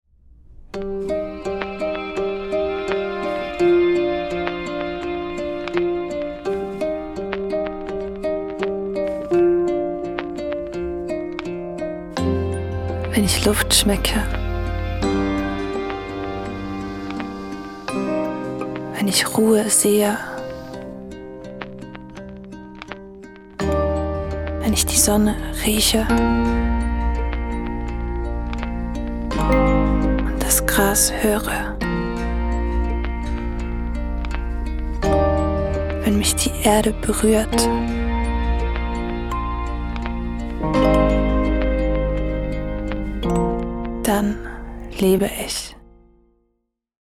Sprecherin Schweizerdeutsch, Sprecherin Berndeutsch, Schweizer Sprecherin in Hamburg
Sprechprobe: Industrie (Muttersprache):
Calida_V2_German_gesprochen.mp3